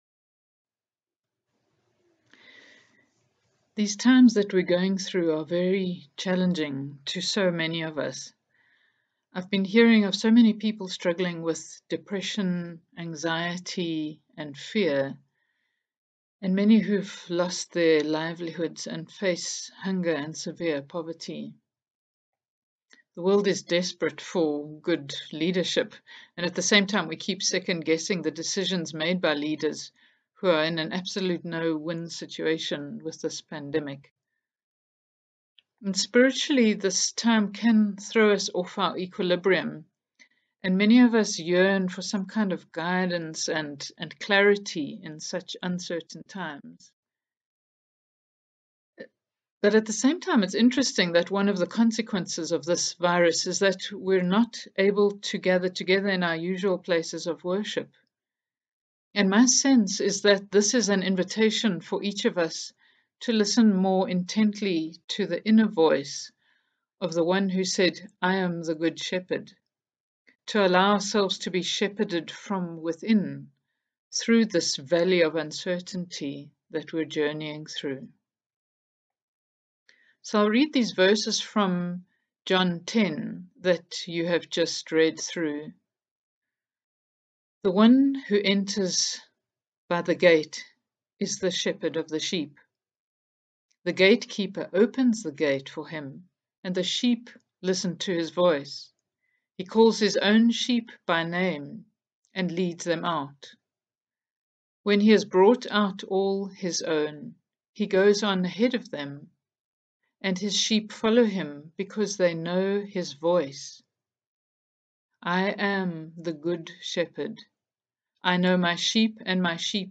After some time of quiet reflection on this scripture passage you can listen to the following talk on the theme of the Good Shepherd.